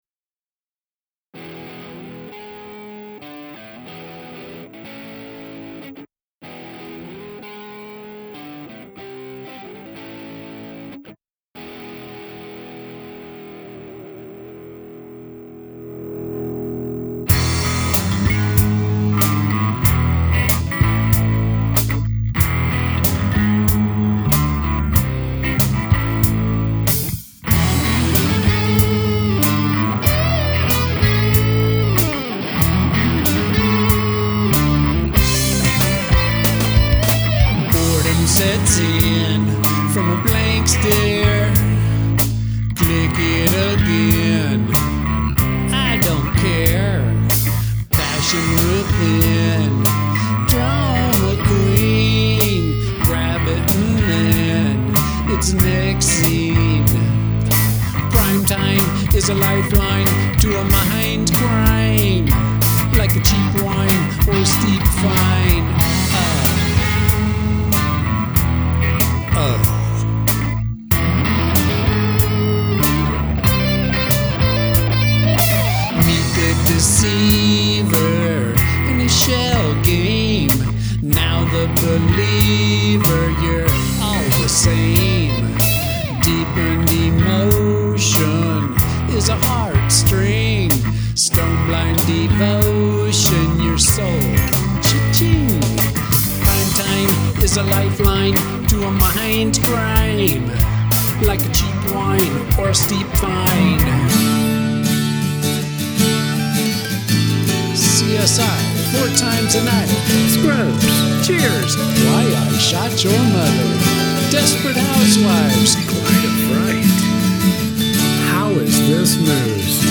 Slow, boring drone